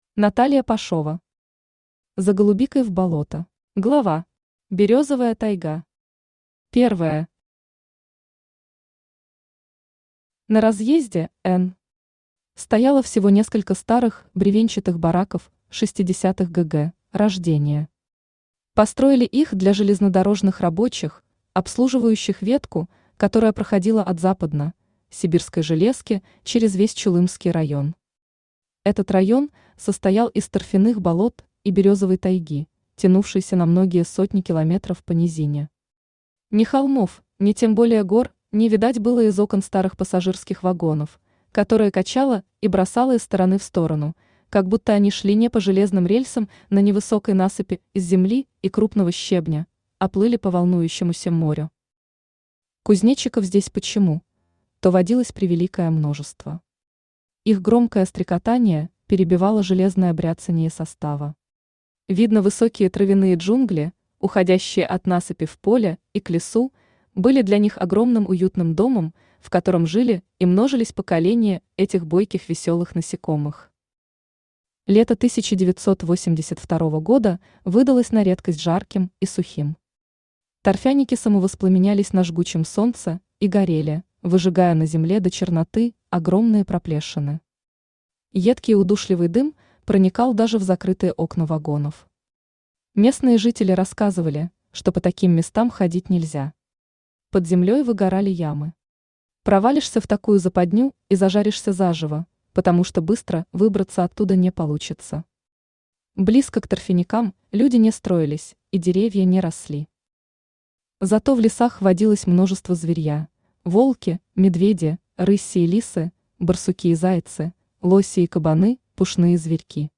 Аудиокнига За голубикой в болото | Библиотека аудиокниг